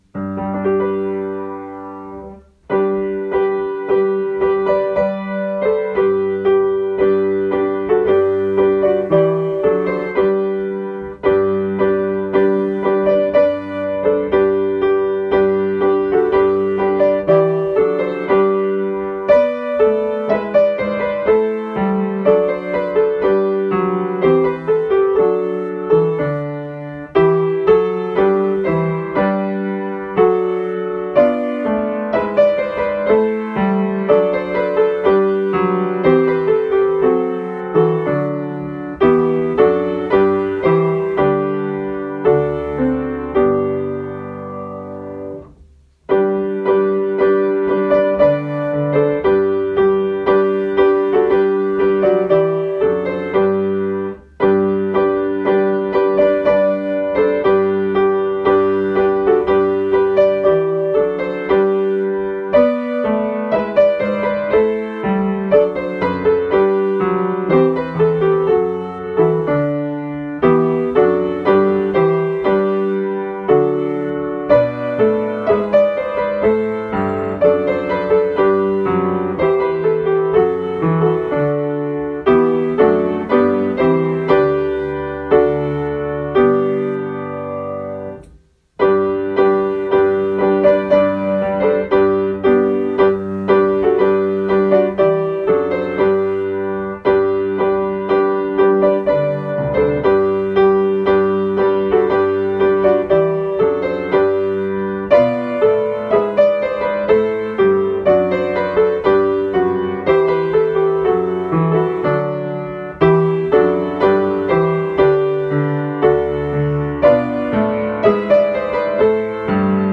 Harmonisé pour 2 voix au piano
Harmonisé au piano en Ré
mu-les-anges-dans-nos-campagnes-harmonisc3a9-au-piano.mp3